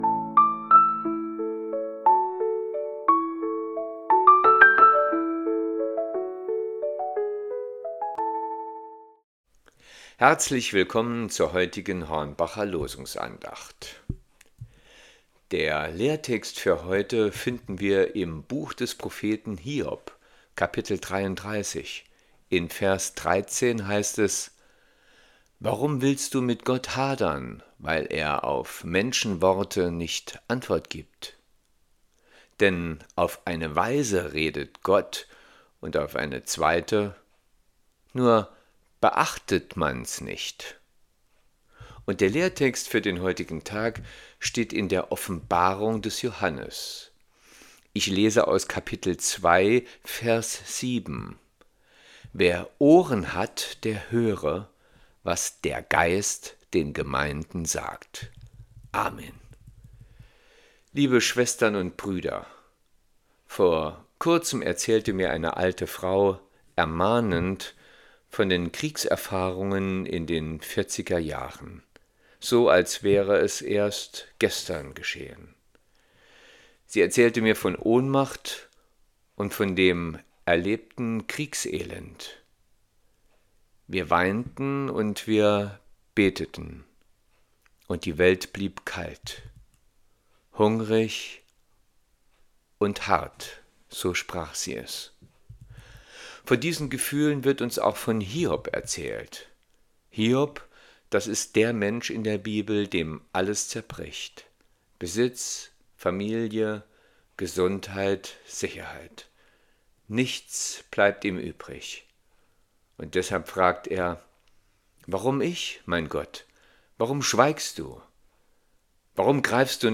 Losungsandacht für Montag, 10.11.2025